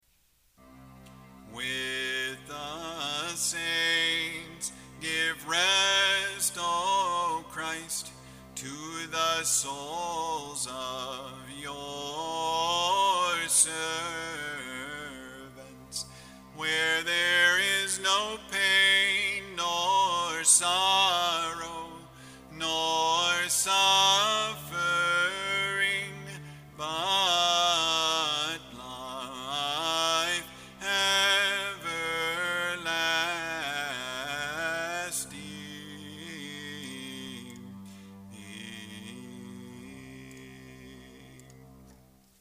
kontakion.mp3